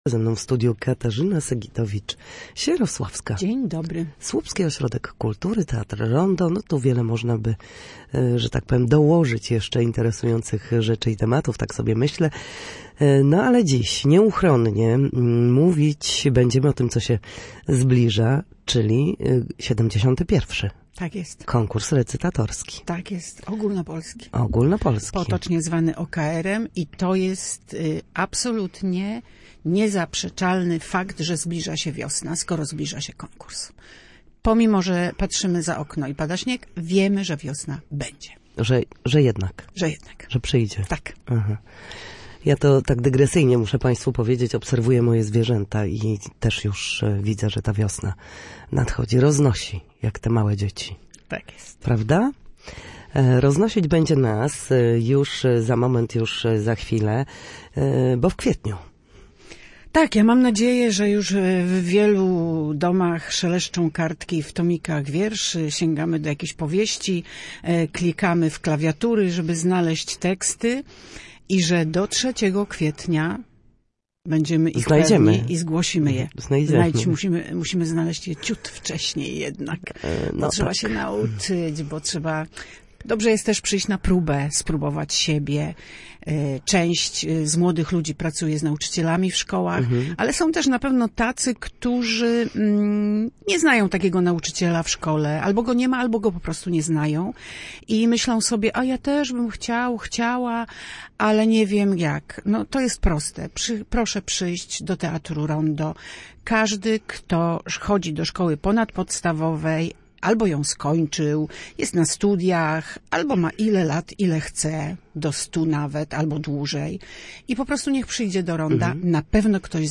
Gościem Studia Słupsk była